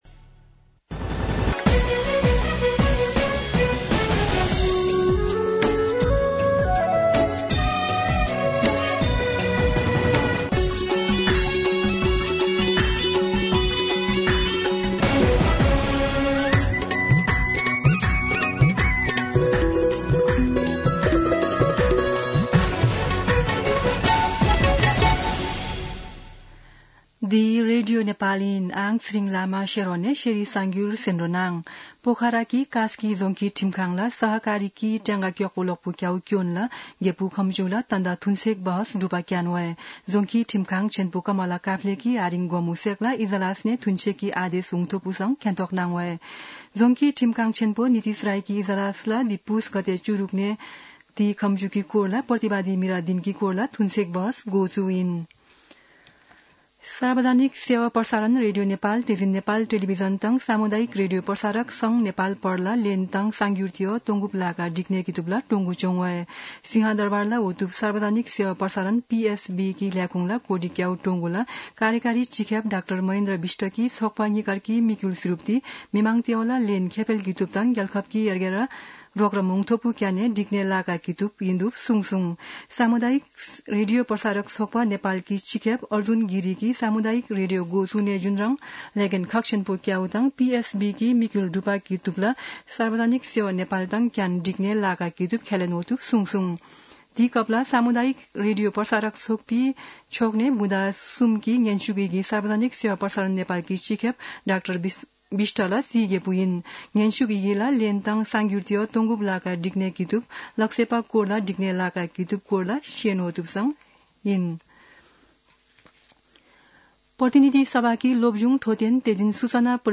शेर्पा भाषाको समाचार : २६ पुष , २०८१
Sherpa-news-3.mp3